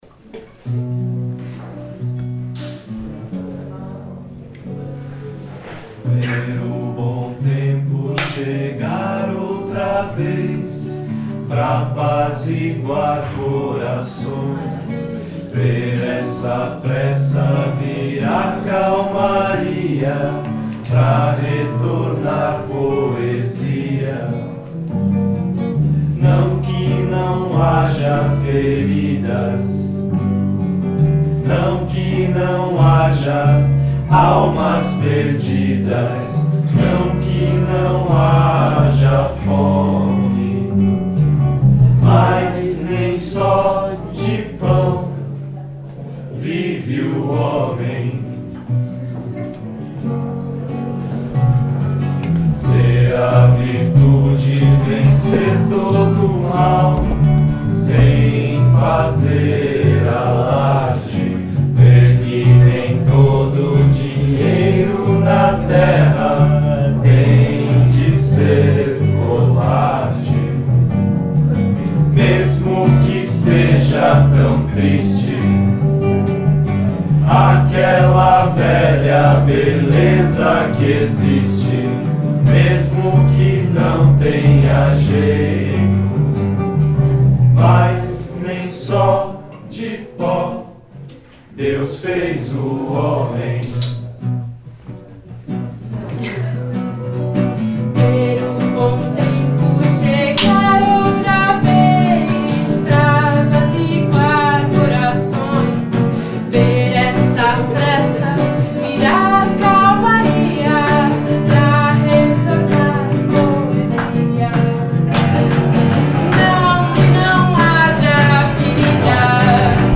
pequenas apresentações em casas de amigos e conhecidos.